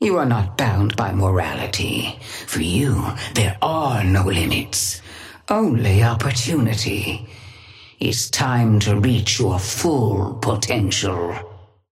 Sapphire Flame voice line - You are not bound by morality.
Patron_female_ally_nano_start_05.mp3